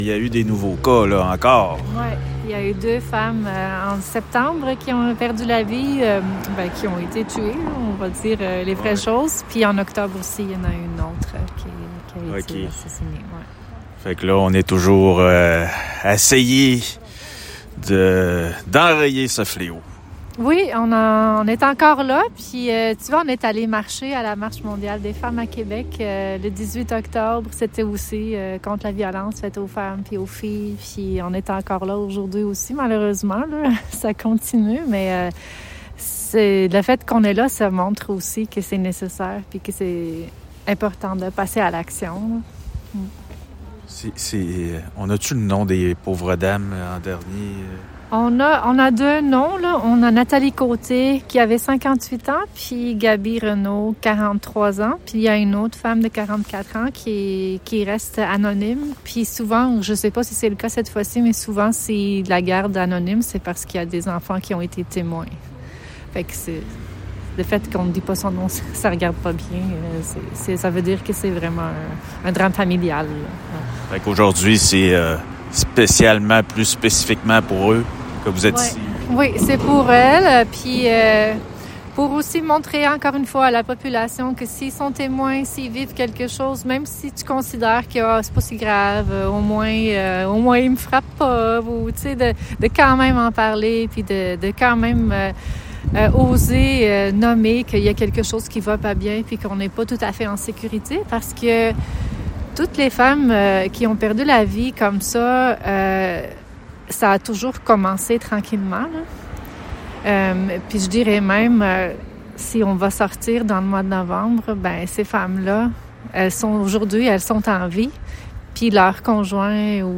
💔 Rassemblements solidaires dans Charlevoix pour dénoncer les féminicides.